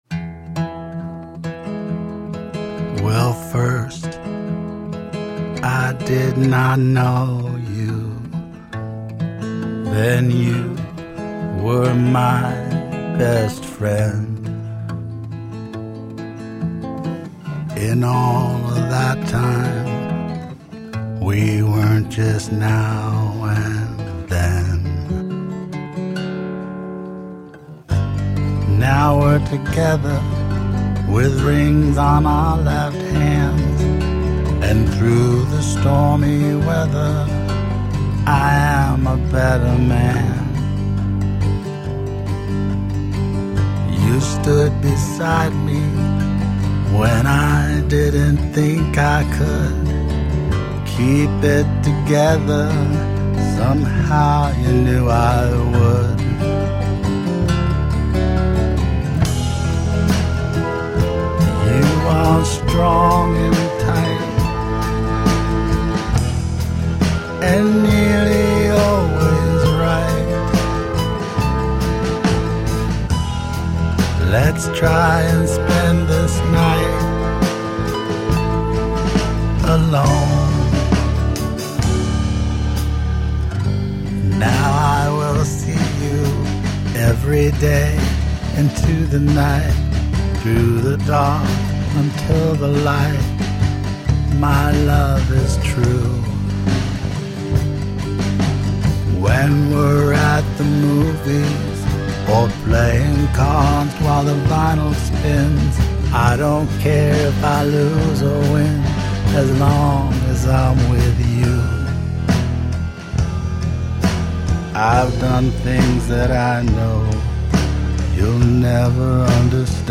An introspective mix of acoustic guitar based songs.
The tracks are arranged around acoustic guitars, mostly.